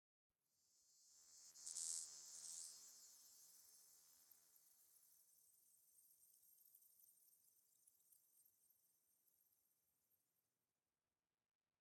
firefly_bush5.ogg